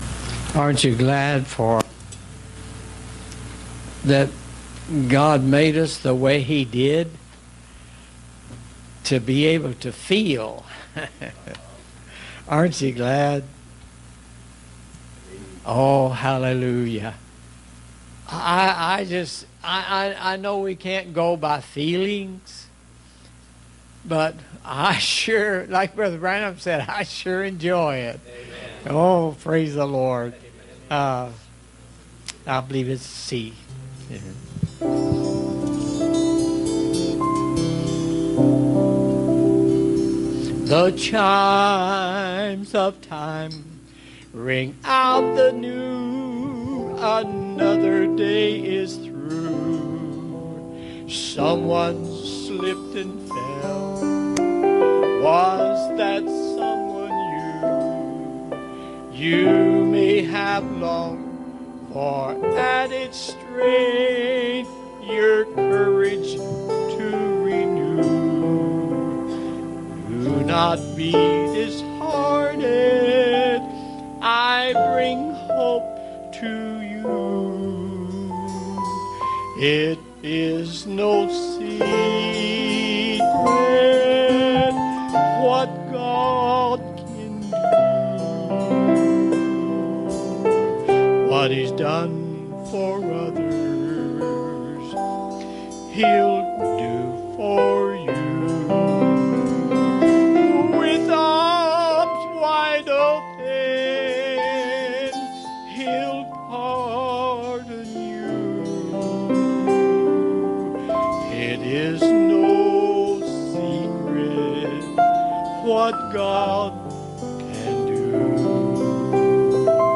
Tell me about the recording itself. Passage: 1 Samuel 16:11 Service Type: Wednesday Evening